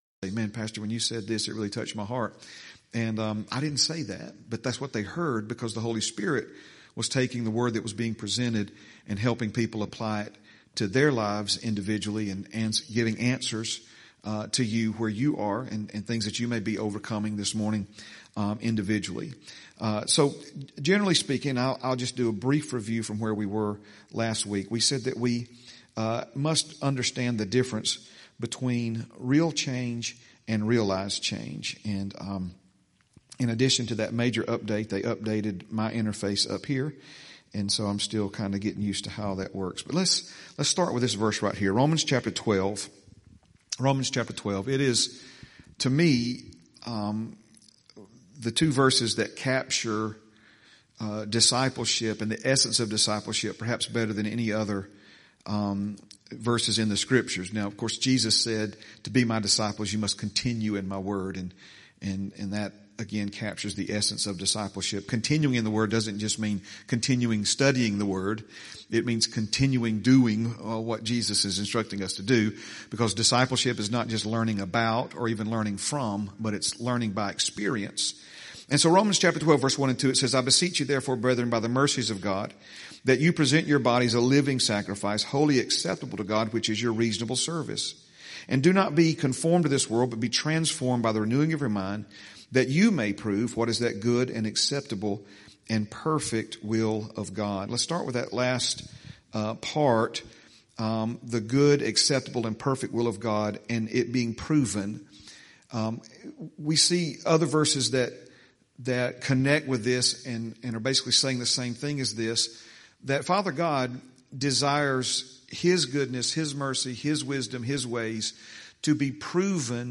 12/28/25 Sunday - Sunday Morning Message